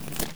pageturn.wav